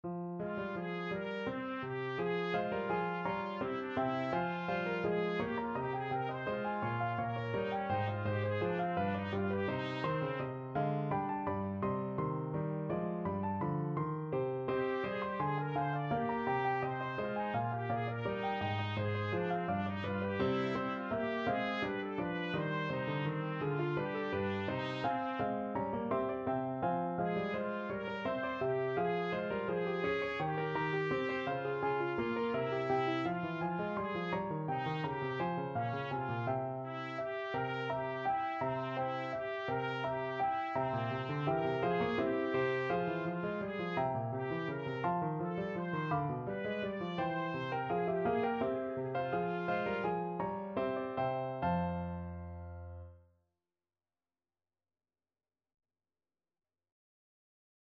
Trumpet
F minor (Sounding Pitch) G minor (Trumpet in Bb) (View more F minor Music for Trumpet )
3/8 (View more 3/8 Music)
C5-D6
Classical (View more Classical Trumpet Music)